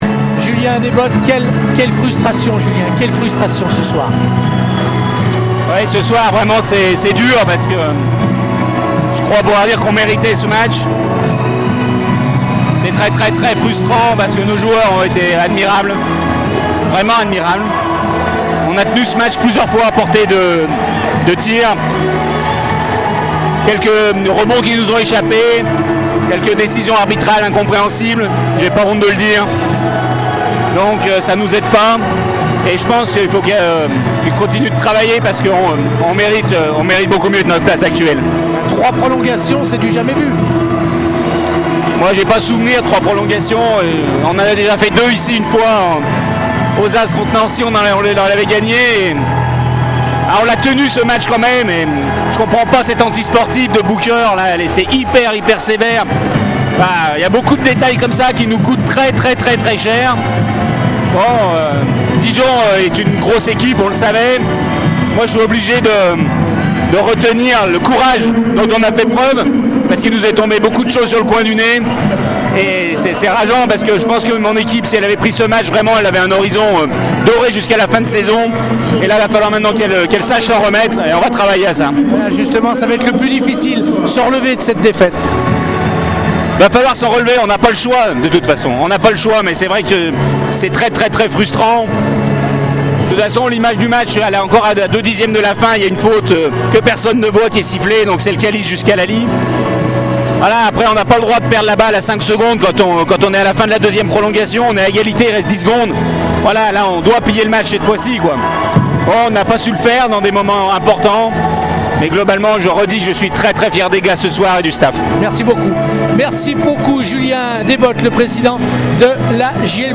les interviews d’après-match pour Radio Scoop